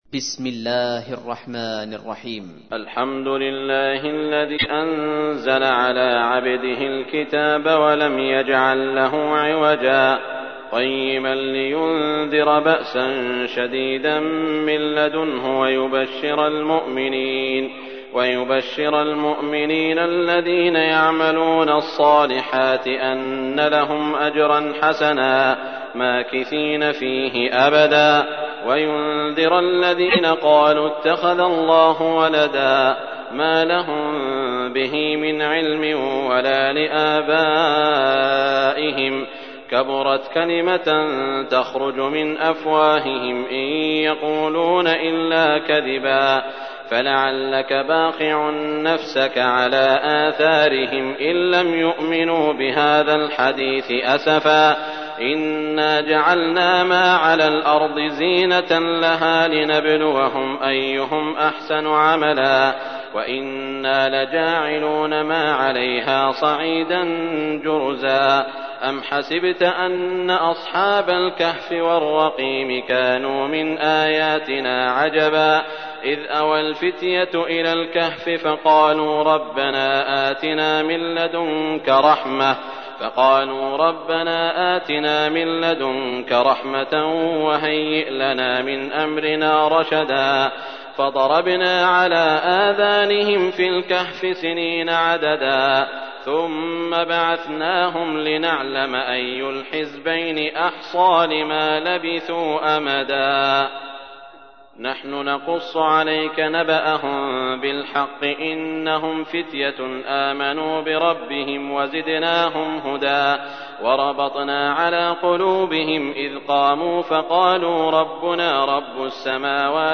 تحميل : 18. سورة الكهف / القارئ سعود الشريم / القرآن الكريم / موقع يا حسين